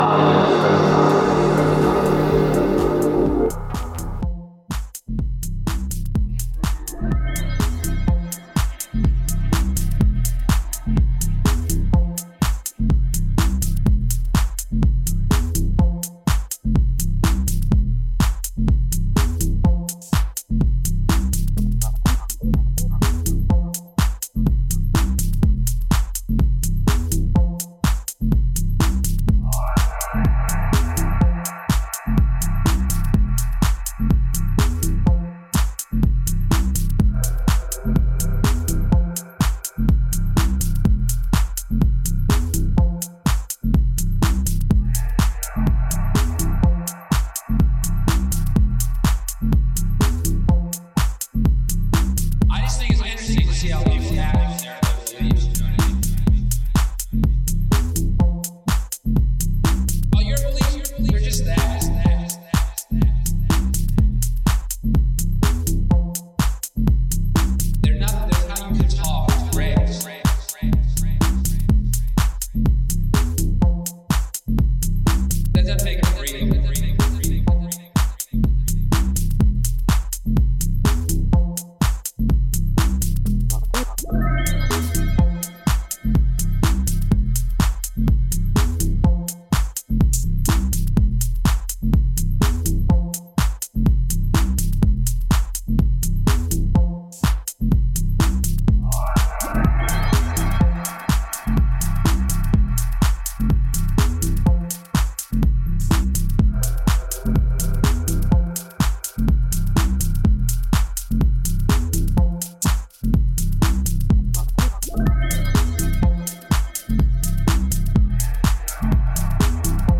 Acid Bass Dancehall